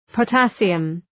Προφορά
{pə’tæsıəm}